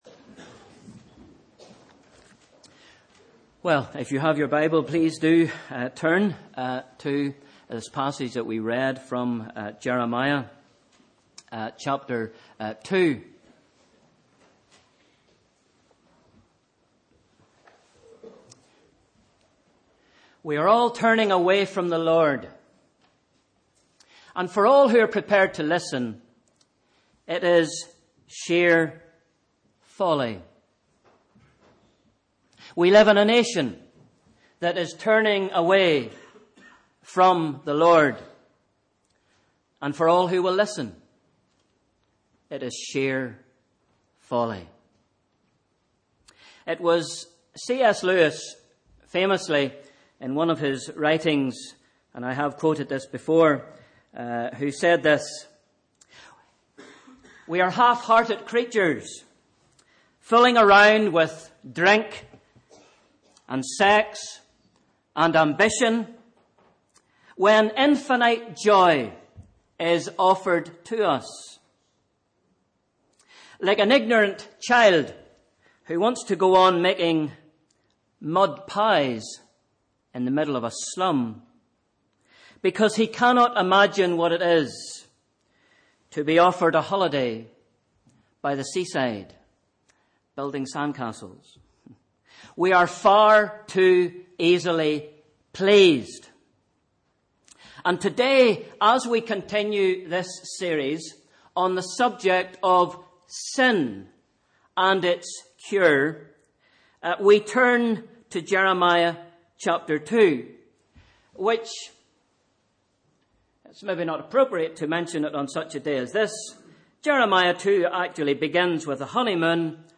Sunday 14th February 2016 – Morning Service